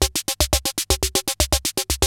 CR-68 LOOPS1 5.wav